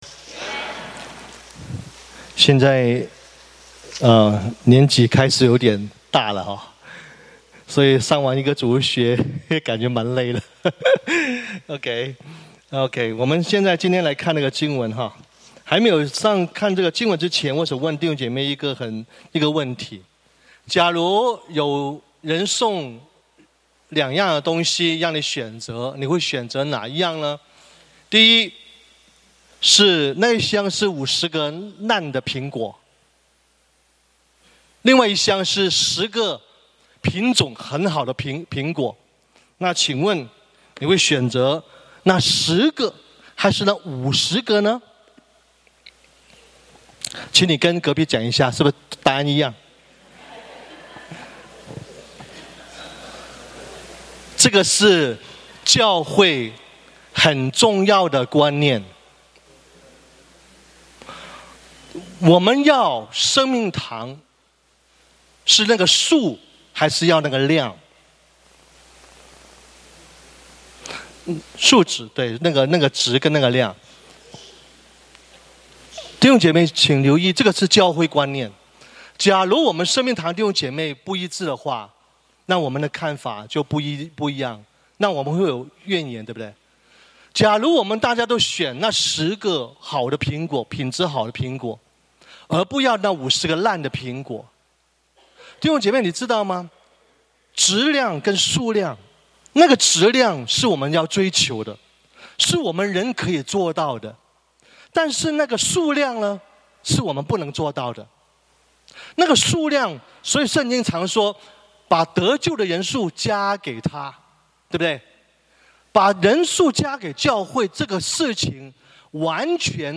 主 日 證 道 | 美城基督生命堂 22/23